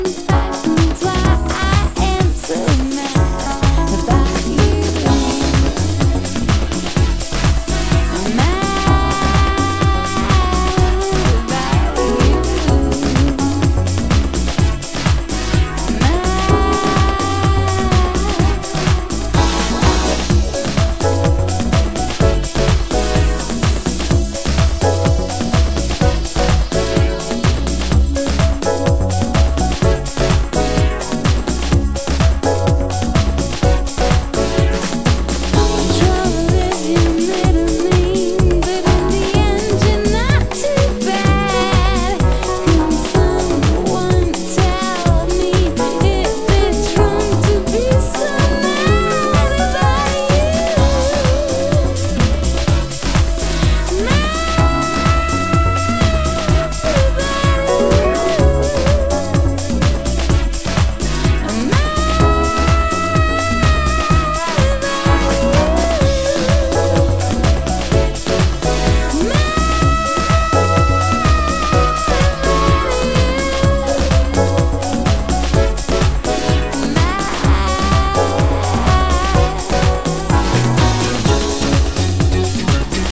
HOUSE MUSIC
FK Vocal Mix